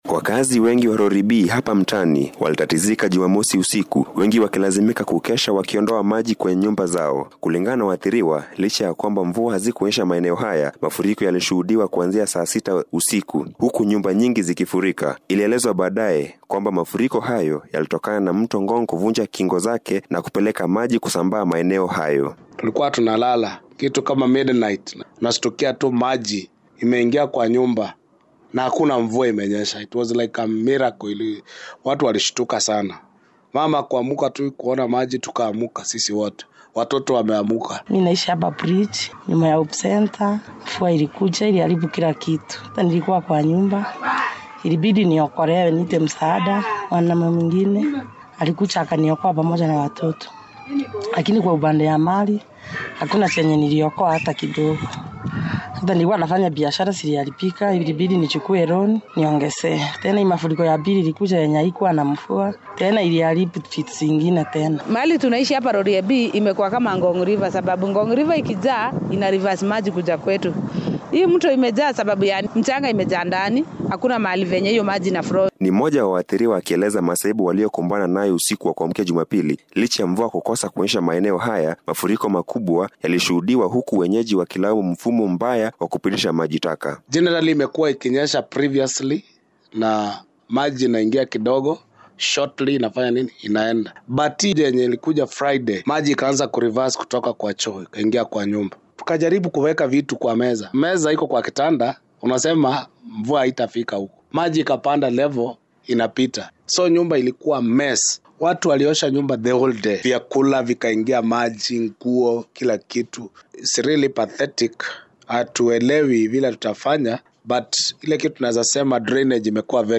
Ni mmoja wa waathiriwa akieleza masaibu waliyokumbana nayo usiku wa kuamkia Jumapili.